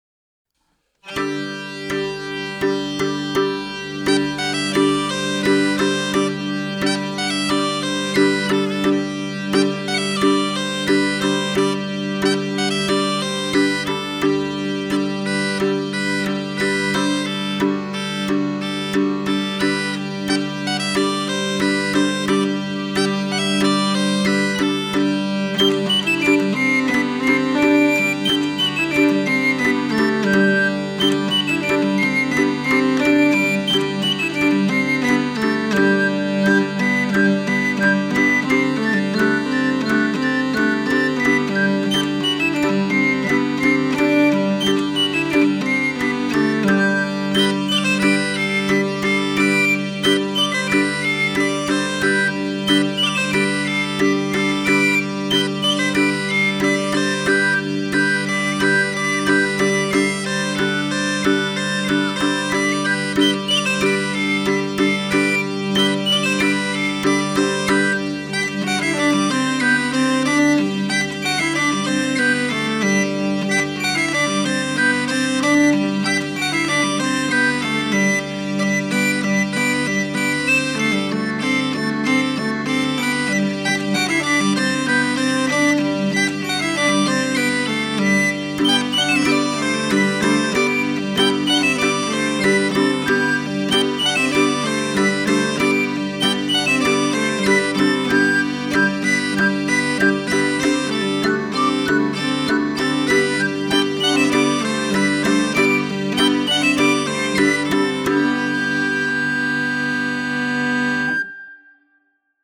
Oiartzungo Lezoti estudioan grabatuta.
Zarrabetea, xirula eta ttun-ttuna